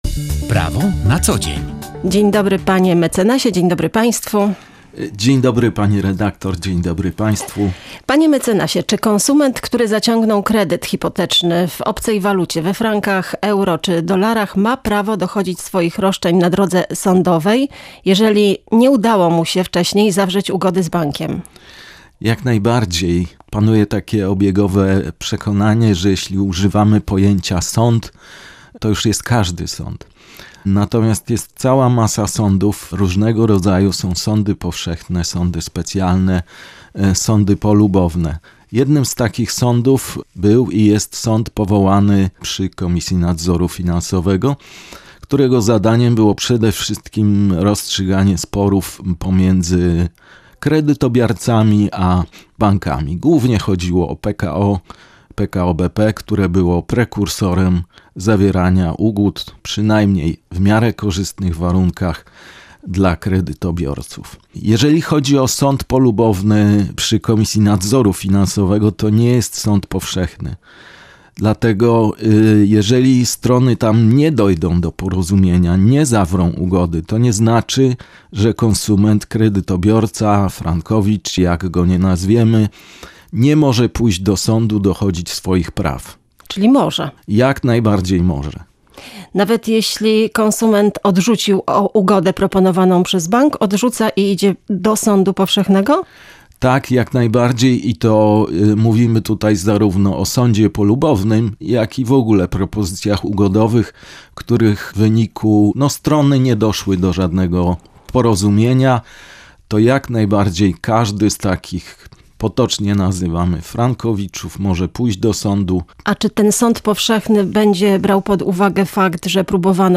Pytamy adwokata